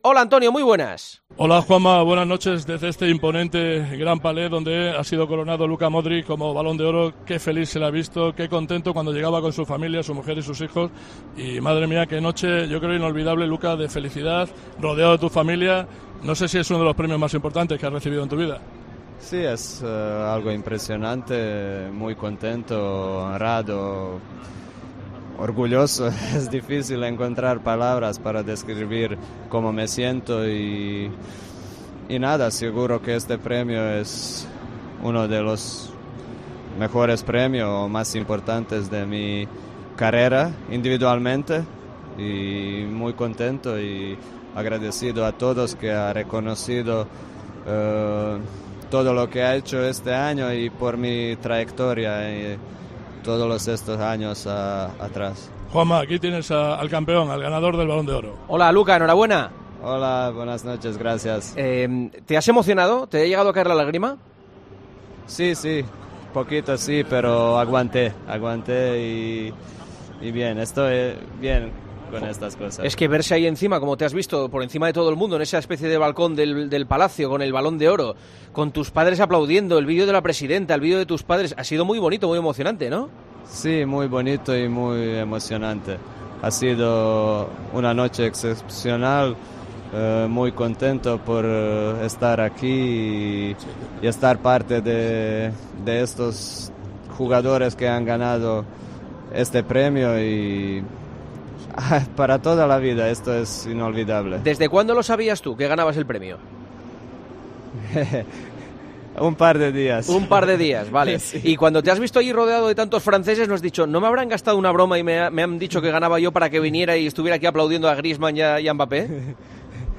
Luka Modric fue el protagonista de El Partidazo de COPE este lunes.